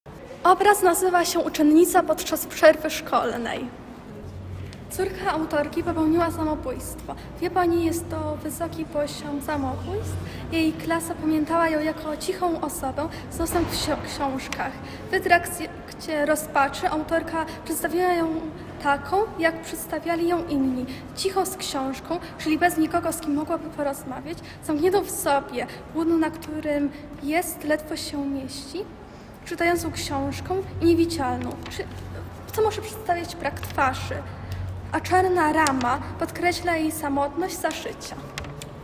Obraz w ruchu. Interwencja: Audioprzewodnik